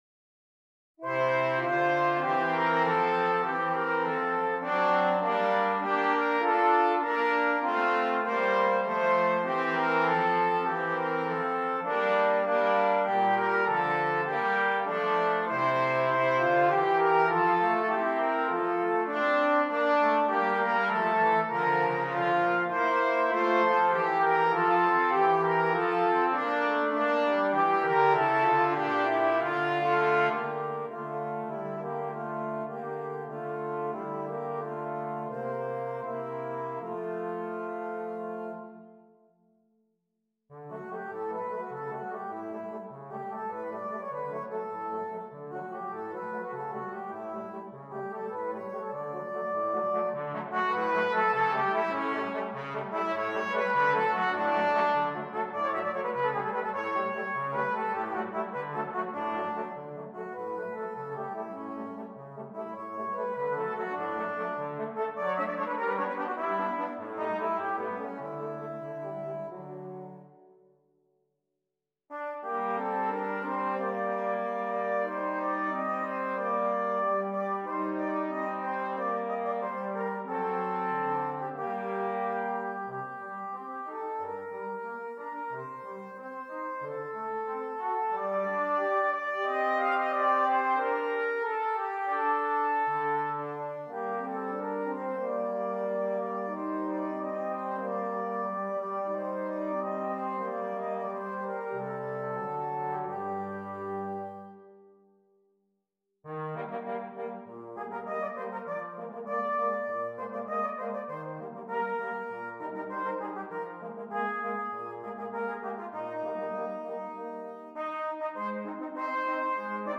Brass Trio